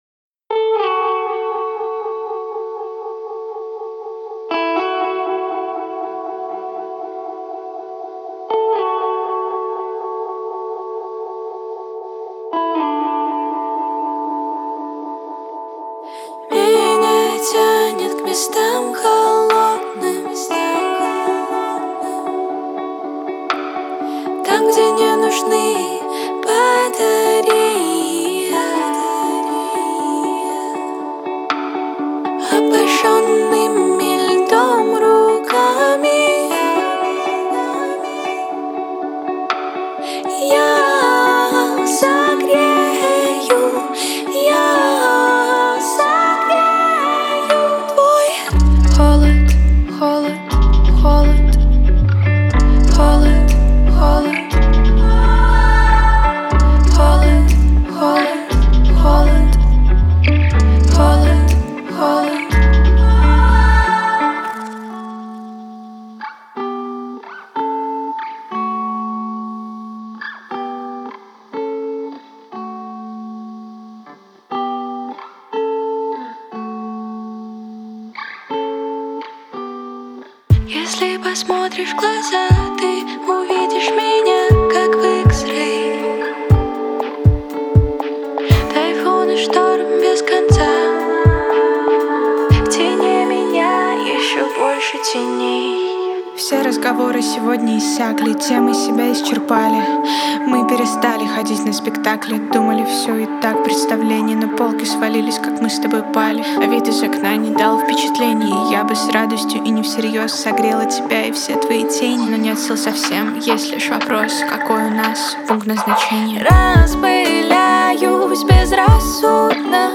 это яркий пример современного поп-рока